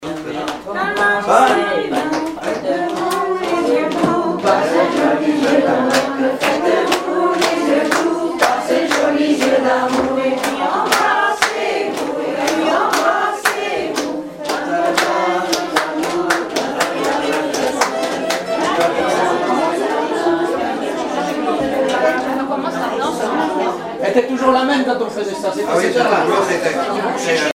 Club d'anciens de Saint-Pierre association
Rondes enfantines à baisers ou mariages
danse : ronde à embrasser
Pièce musicale inédite